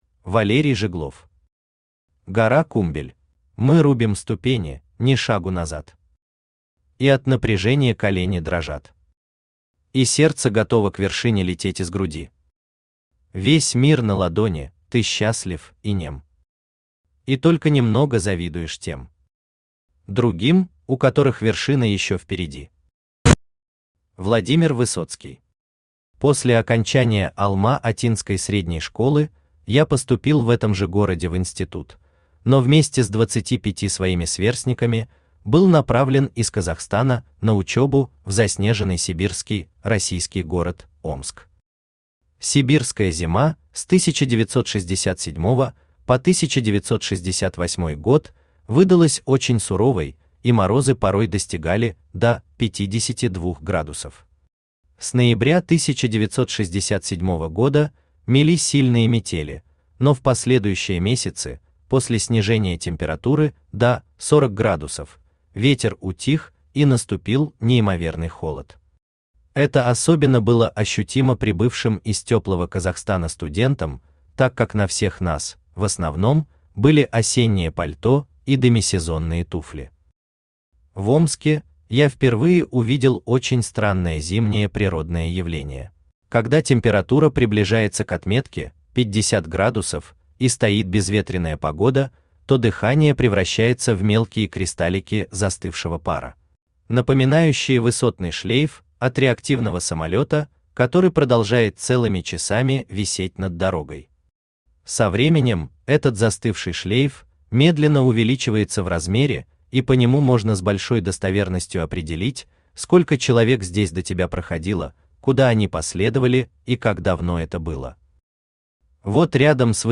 Аудиокнига Гора Кумбель | Библиотека аудиокниг
Aудиокнига Гора Кумбель Автор Валерий Жиглов Читает аудиокнигу Авточтец ЛитРес.